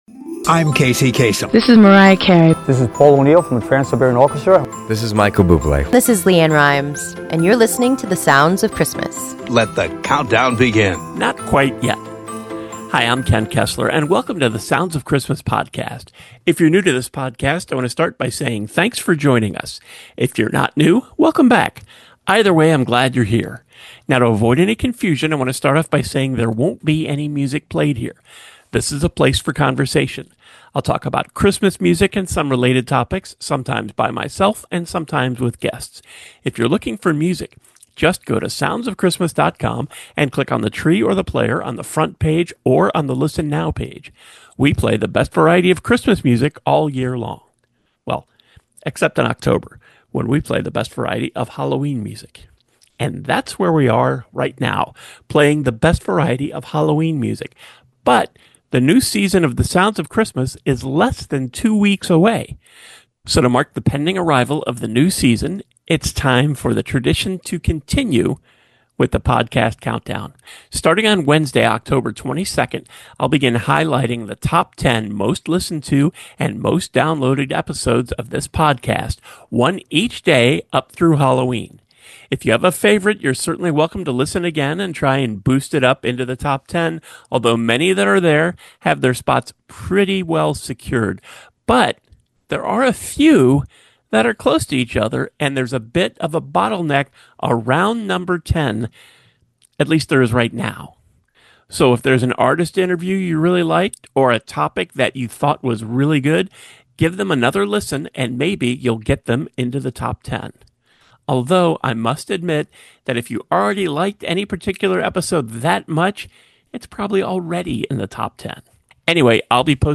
On this episode, we bring you the the 5th Anniversary presentation of our made-for-podcast Christmas movie: “A Bomb For Christmas.”